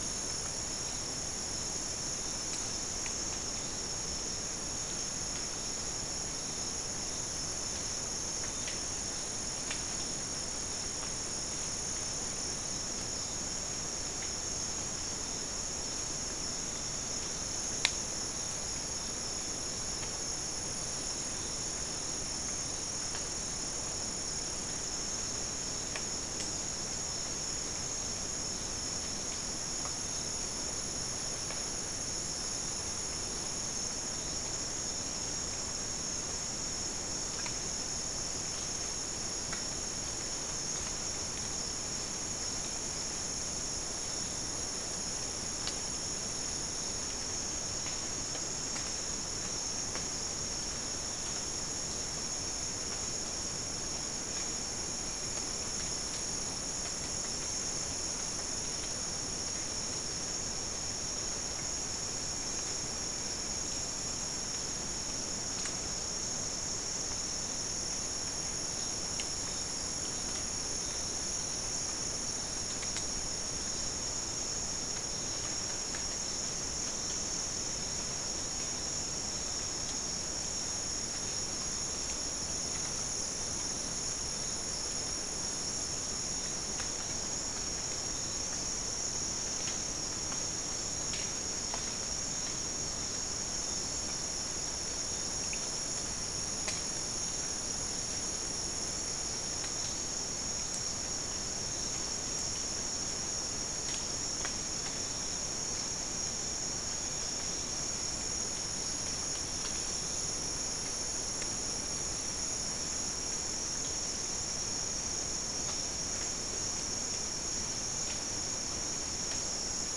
Soundscape Recording Location: South America: Guyana: Rock Landing: 1
Recorder: SM3